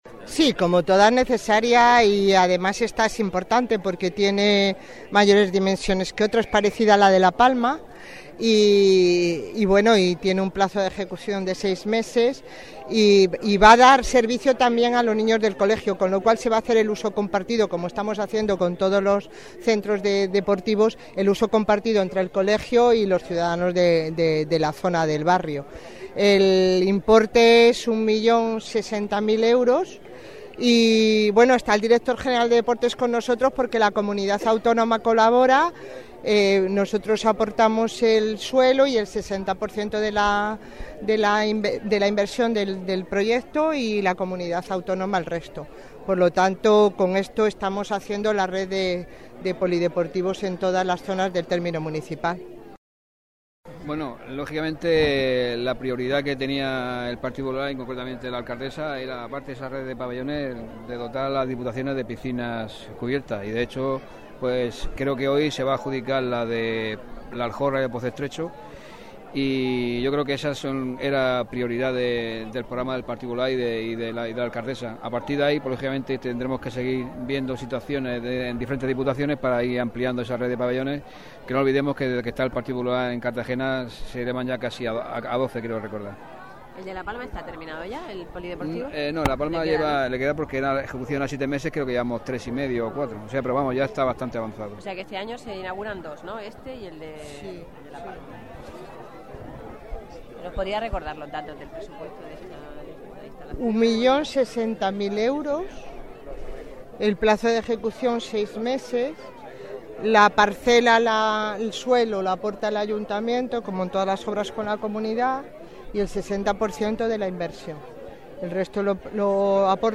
Acto de colocación de la primera piedra del pabellón de El Albujón